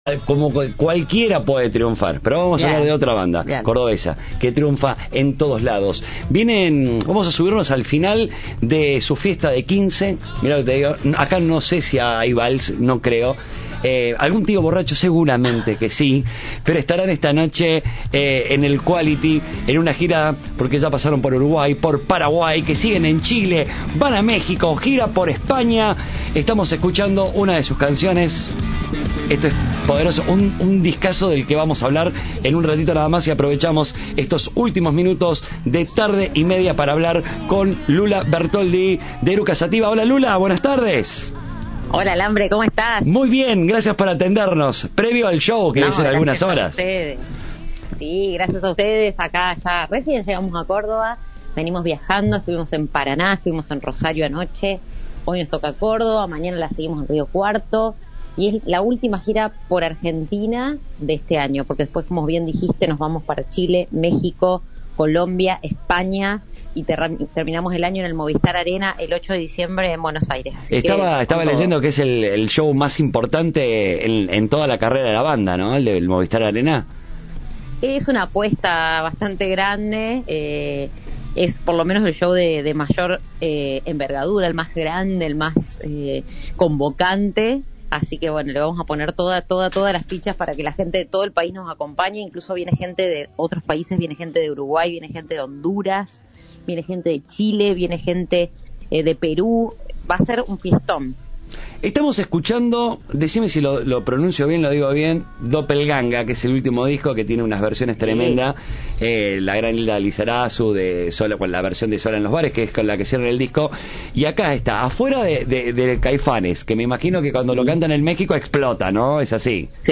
En diálogo con Cadena 3, adelantaron que "será un fiestón".